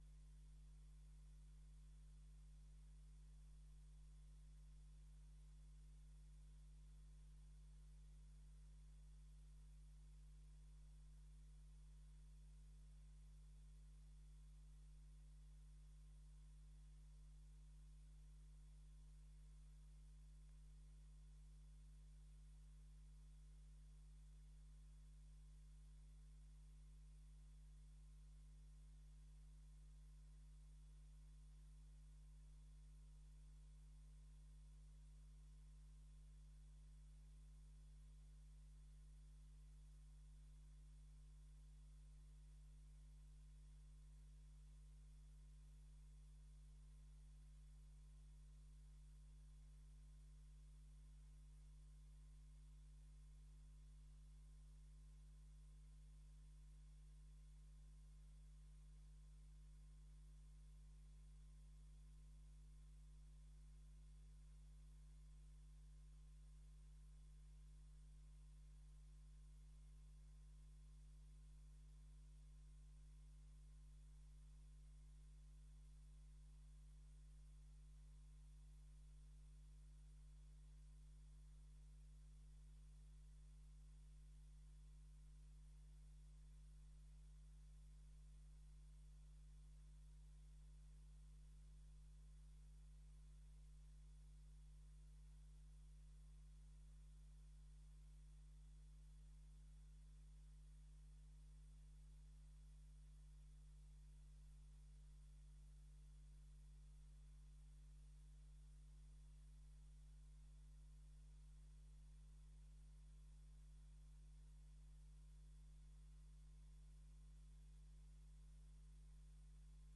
Raadscommissie 21 oktober 2024 19:30:00, Gemeente Dalfsen
Locatie: Raadzaal